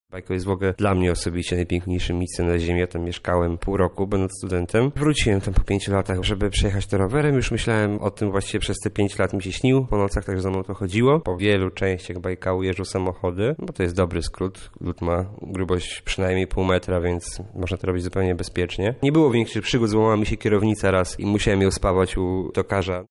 Bajkał „Ice Trip” to w skrócie dwa rowery, jedno jezioro i dużo lodu. – mówi podróżnik.